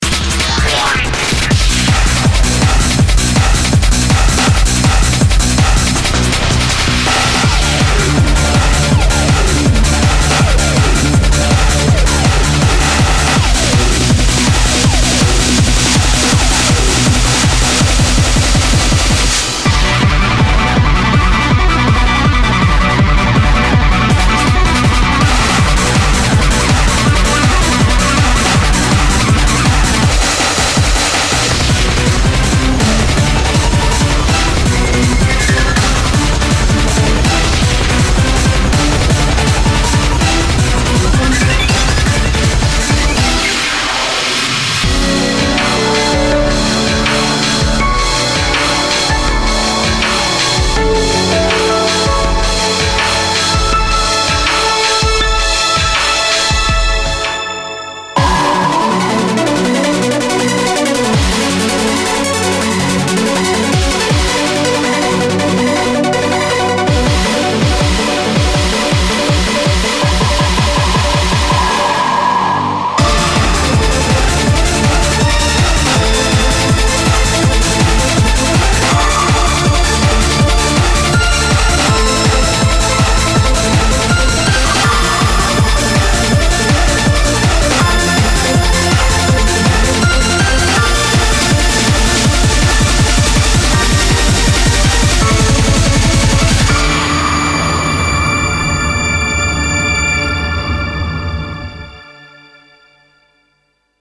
BPM41-324
Audio QualityMusic Cut